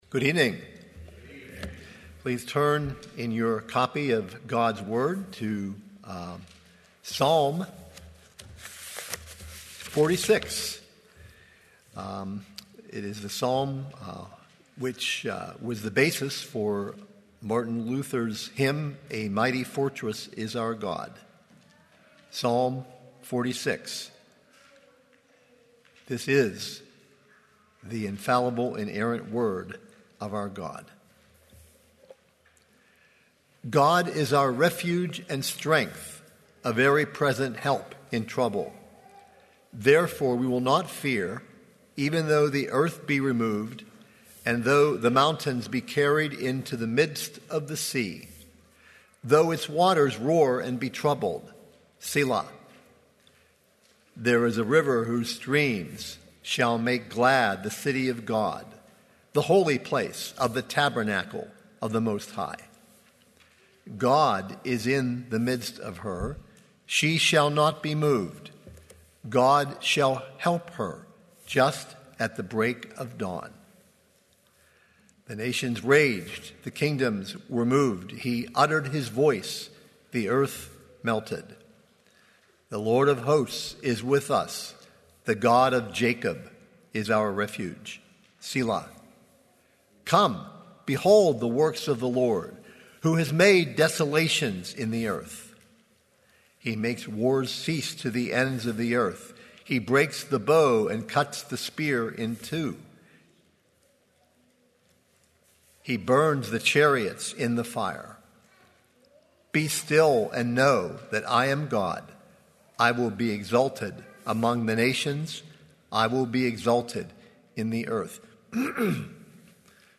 00:00 Download Copy link Sermon Text Psalm 46